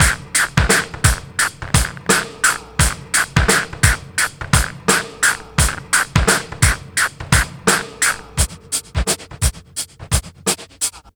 Index of /90_sSampleCDs/Best Service ProSamples vol.24 - Breakbeat [AKAI] 1CD/Partition A/TRIBEVIBE086